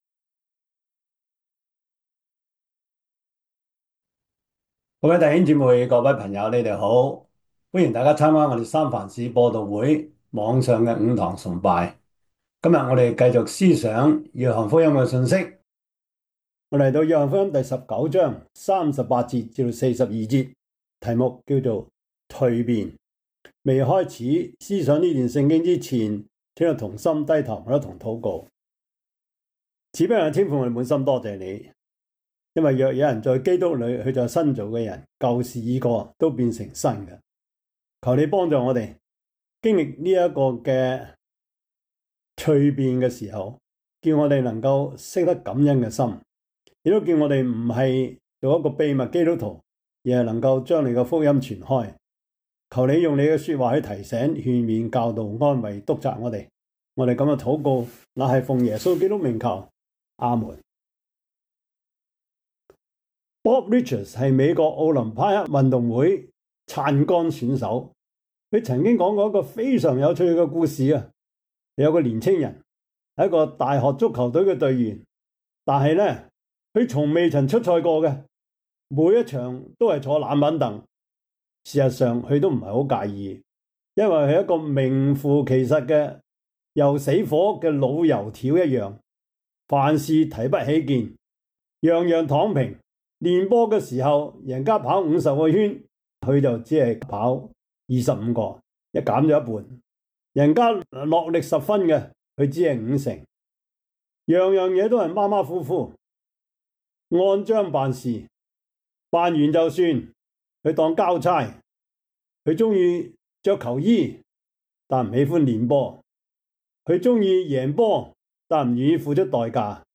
約翰福音 19:36-42 Service Type: 主日崇拜 約翰福音 19:36-42 Chinese Union Version